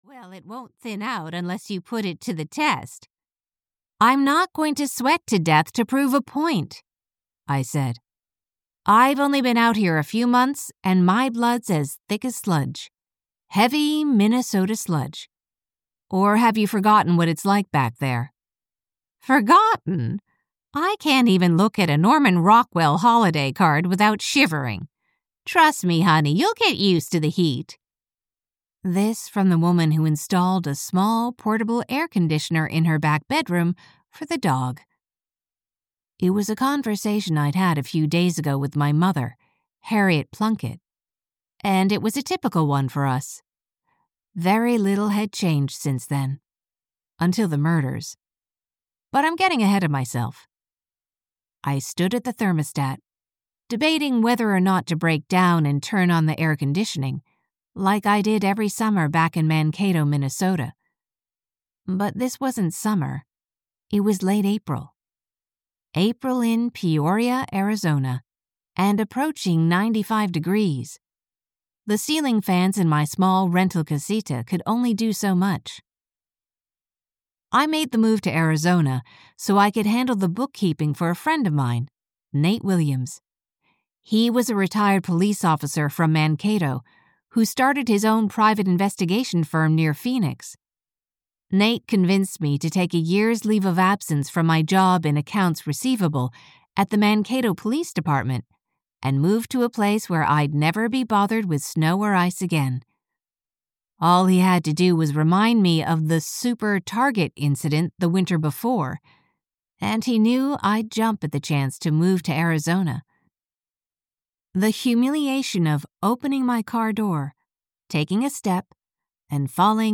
Audio knihaDitched for Murder (EN)
Ukázka z knihy